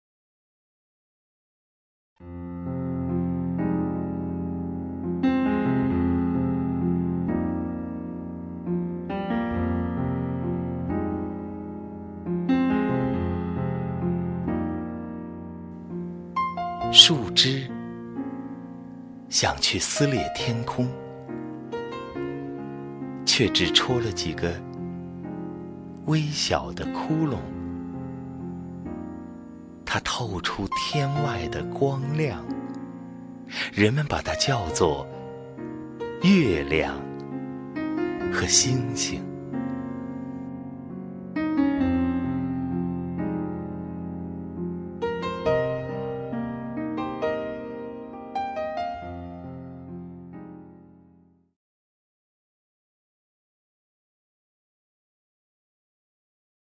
赵屹鸥朗诵：《星月的来由》(顾城) 顾城 名家朗诵欣赏赵屹鸥 语文PLUS